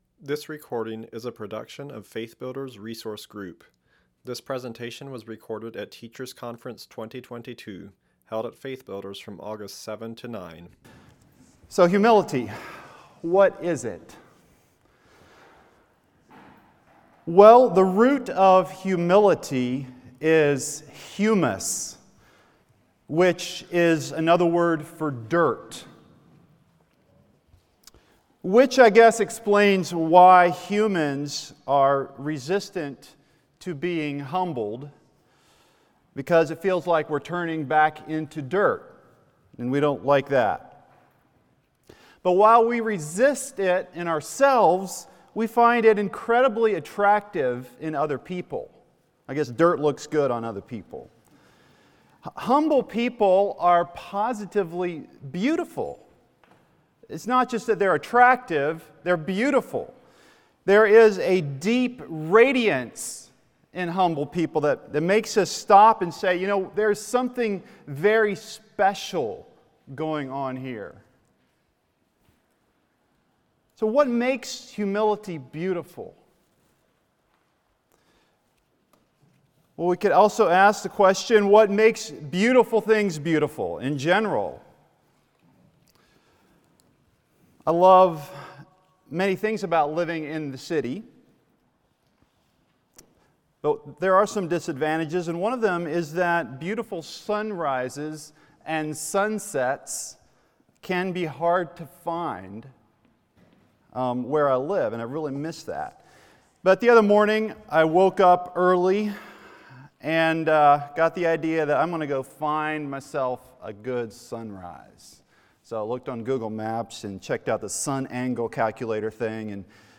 Home » Lectures » Appreciating the Beauty of Humility